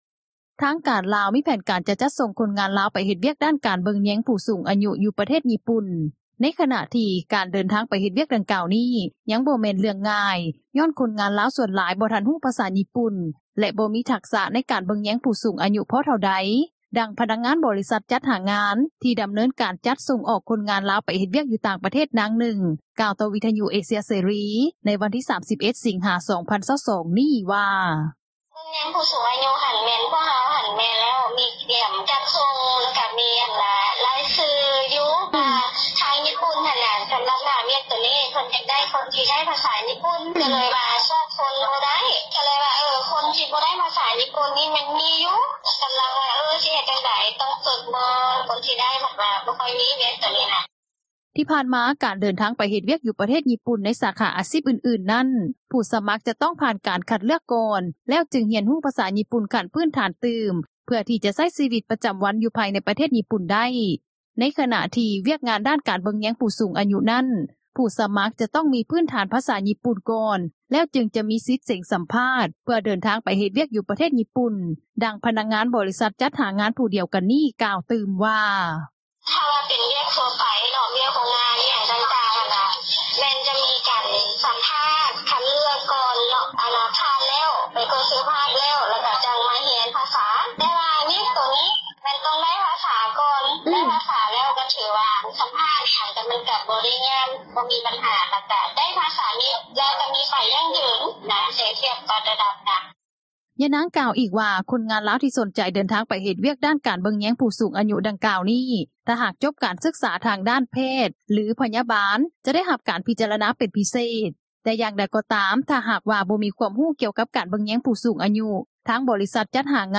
ດັ່ງພະນັກງານ ບໍຣິສັດຈັດຫາງານ ທີ່ດໍາເນີນການຈັດສົ່ງອອກຄົນງານລາວ ໄປເຮັດວຽກຢູ່ຕ່າງປະເທດ ນາງນຶ່ງ ກ່າວຕໍ່ວິທຍຸເອເຊັຽເສຣີ ໃນມື້ວັນທີ່ 31 ສິງຫາ 2022 ນີ້ວ່າ:
ດັ່ງຄົນງລາວ ທີ່ຕ້ອງການເດີນທາງ ໄປເຮັດວຽກ ຢູ່ປະເທດຍີ່ປຸ່ນ ນາງນຶ່ງ ກ່າວວ່າ: